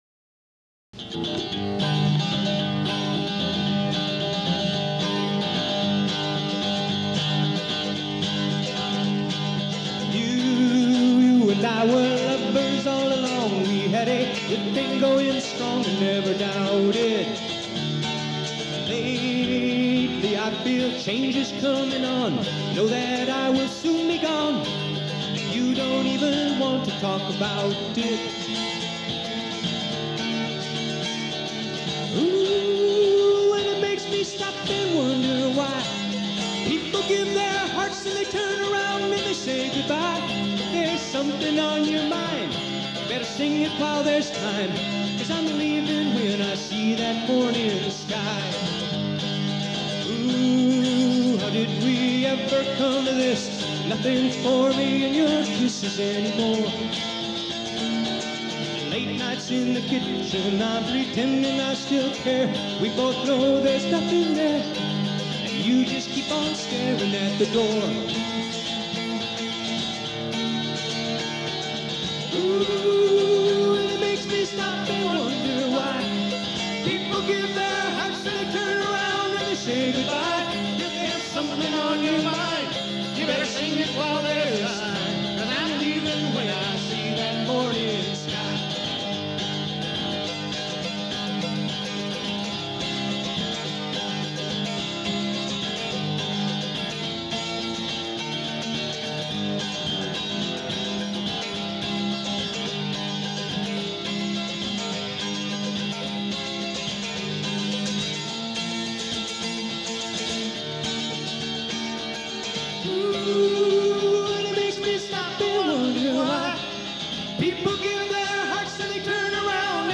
Category Rock/Pop
Studio/Live Live
mandolin, guitar and vocals
bass
drums and percussion